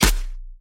More turret SFX
shootAlt.ogg